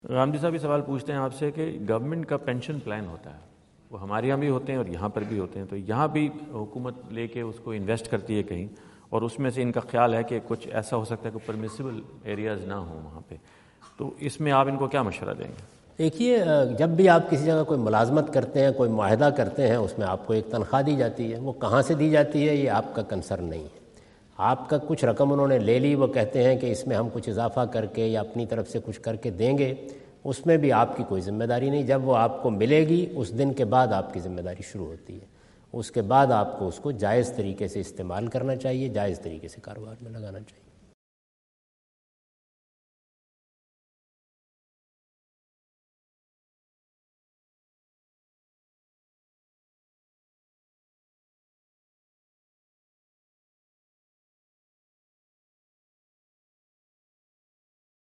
Javed Ahmad Ghamidi answer the question about "Pension Plans for Employees" asked at North Brunswick High School, New Jersey on September 29,2017.
جاوید احمد غامدی اپنے دورہ امریکہ 2017 کے دوران نیوجرسی میں "ملازمین کے لیے پینشن کی سہولت" سے متعلق ایک سوال کا جواب دے رہے ہیں۔